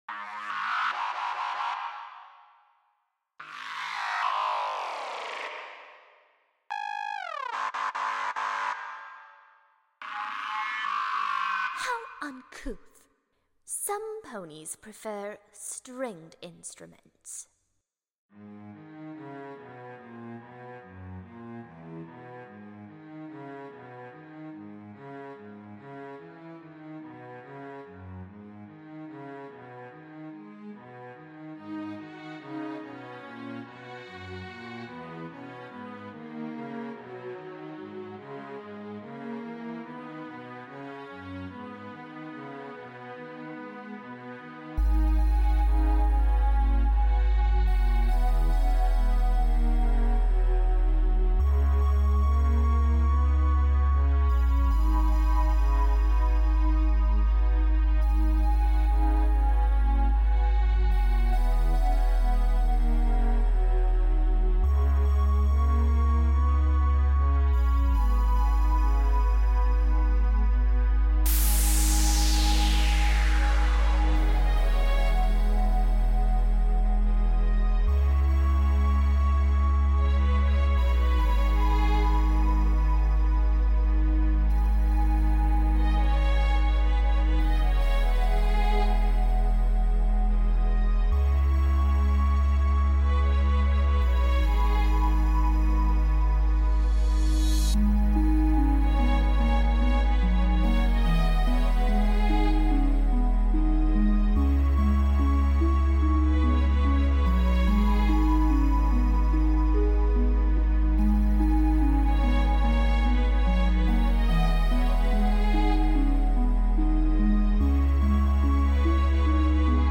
This enigmatic DJ sets the bass booster up to maximum power.
genre:remix